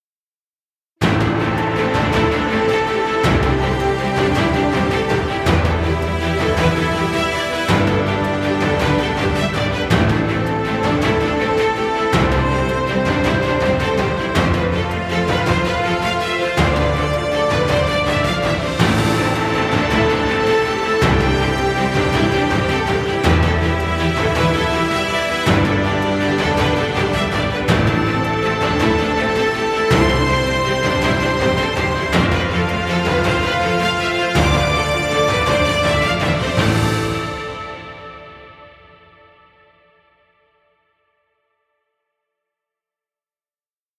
Epic inspirational music.